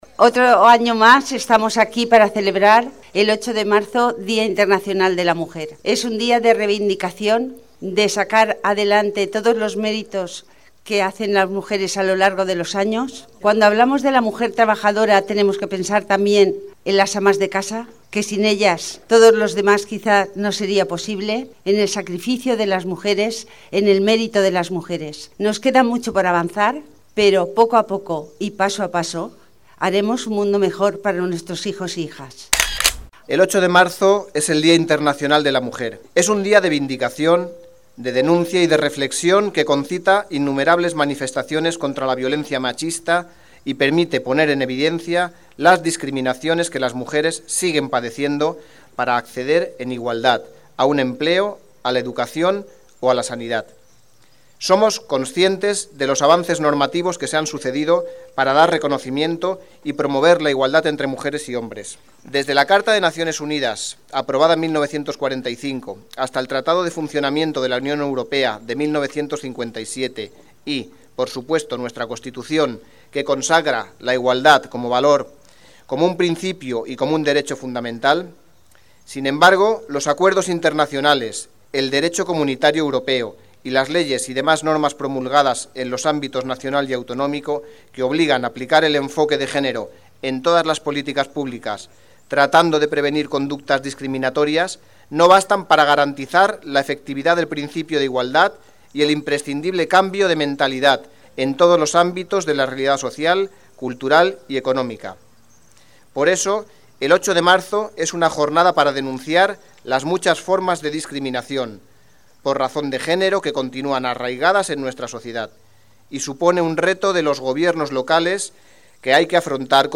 La programación de la Semana de la Mujer con la que la Concejalía de Igualdad y Mujer conmemora el Día de la Mujer ha tenido hoy su jornada central con la Marcha de Mujeres que ha salido desde la Plaça Vella hasta el Parque del Oeste, donde la concejala de Igualdad y Mujer, Bienvenida Algarra, se ha dirigido a las mujeres para agradecerles su “trabajo diario y no reconocido en favor de la sociedad”.
El alcalde, Armando Esteve, ha leído el Manifiesto preparado por la Federación Española de Municipios y Provincias, en el que se recuerda “la responsabilidad de los ayuntamientos en la consecución de una sociedad justa, democrática e igualitaria”, y ha expresado a las asociaciones de mujeres de la localidad el apoyo de la corporación municipal en todas sus actividades.
Corte-Manifiesto.mp3